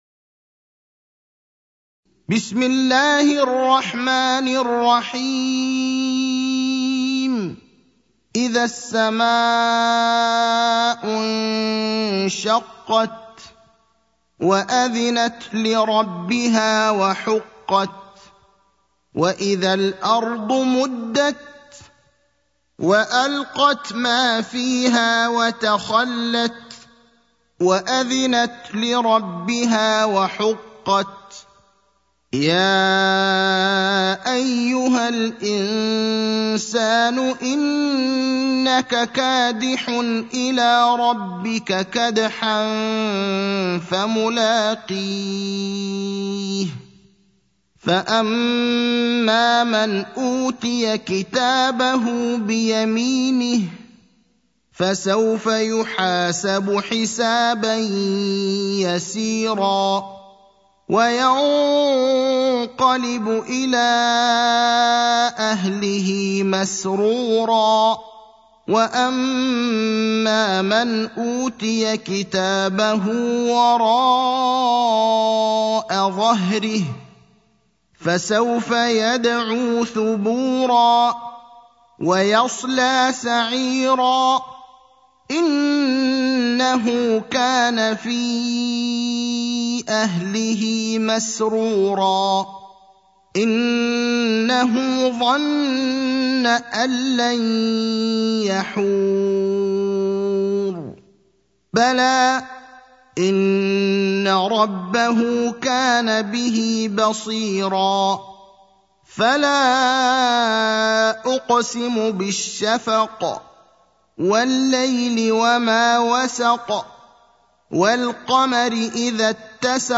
المكان: المسجد النبوي الشيخ: فضيلة الشيخ إبراهيم الأخضر فضيلة الشيخ إبراهيم الأخضر الانشقاق (84)